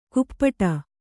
♪ kuppaṭa